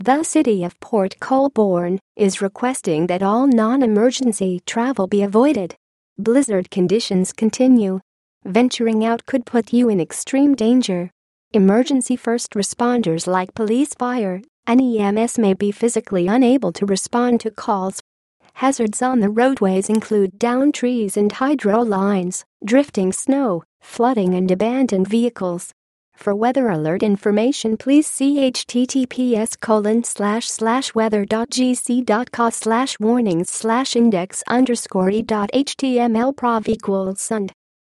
At the end of both of them, the AI voice tried to give a website for Environment Canada that started with https: and ended with God knows what. By the end, the message was so garbled no one could possibly know what "she" said.
The text-to-voice audio file is here: